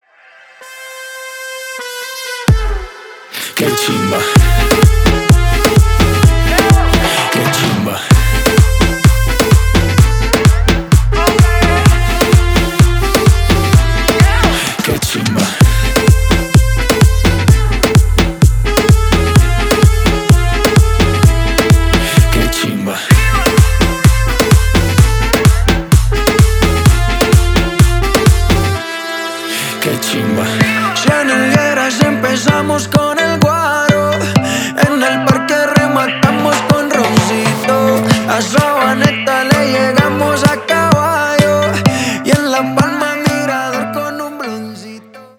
• Качество: 320, Stereo
мужской голос
ритмичные
заводные
Dance Pop
латина
Latin Pop
Стиль: latin urban.